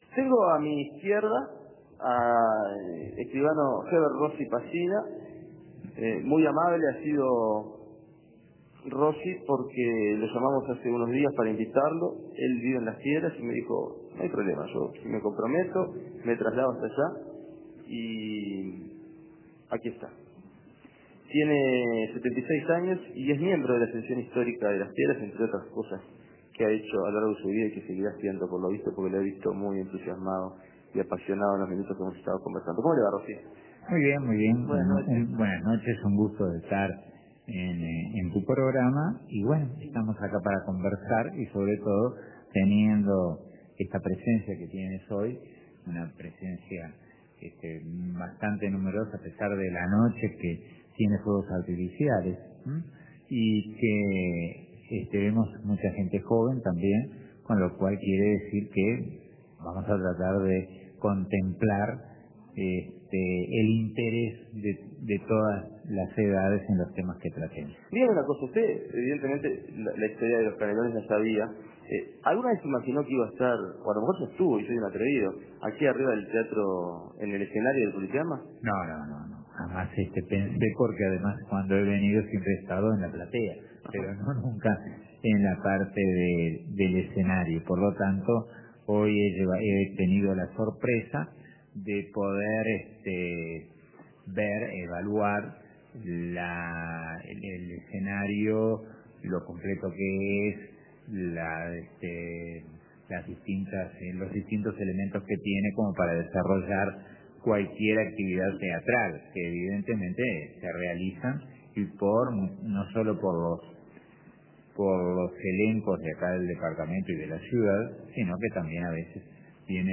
Primera parte de la charla